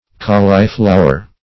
Colliflower \Col"li*flow`er\, n.